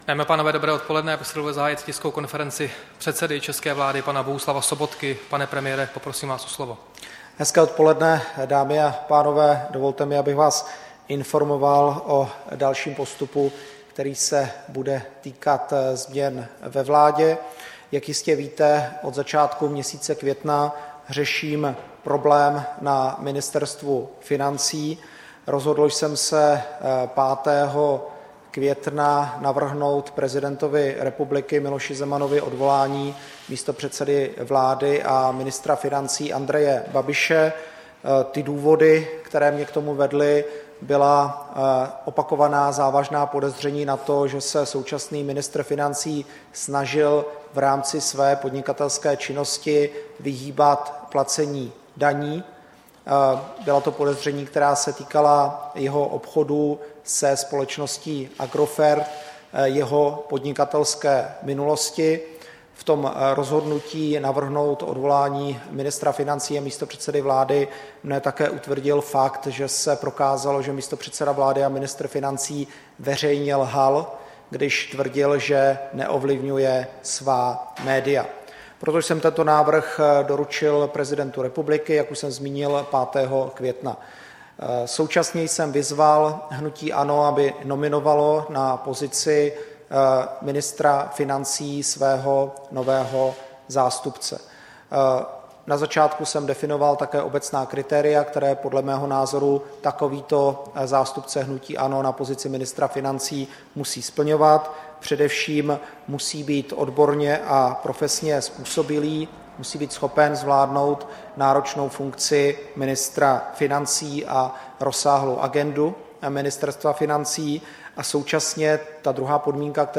Tisková konference k nominaci Ivana Pilného na post ministra financí, 17. května 2017